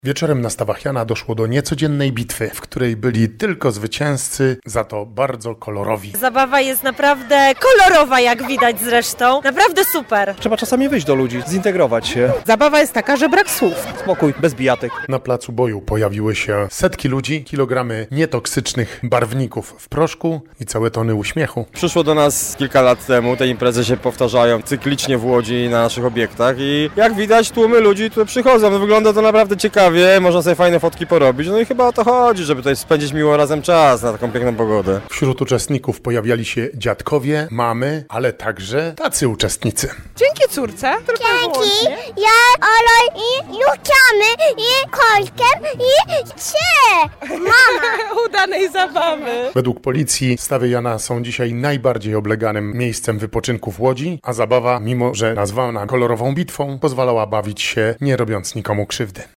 Kolor Fest to wydarzenie w ramach ogólnopolskiej trasy, zainspirowanej hinduistycznym świętem radości Holi. Posłuchaj, jak się bawiono w Łodzi: